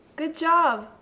good job positive,
good-job-sincere.wav